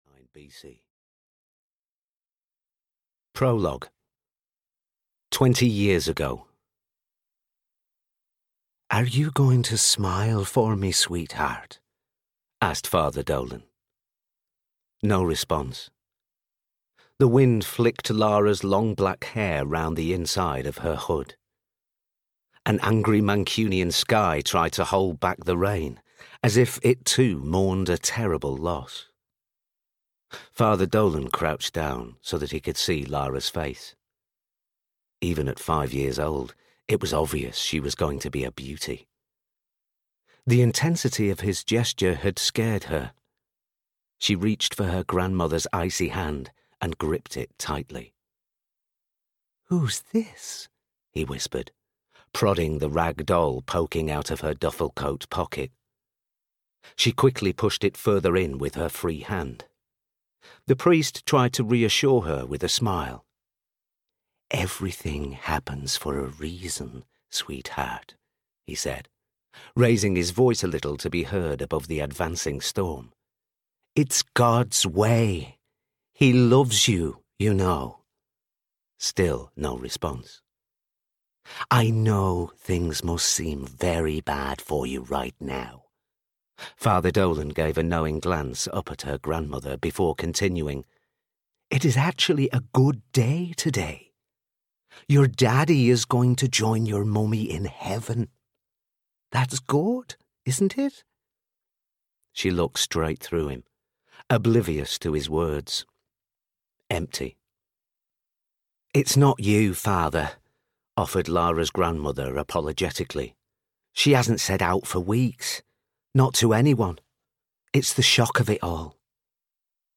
Cut-Throat Defence (EN) audiokniha
Ukázka z knihy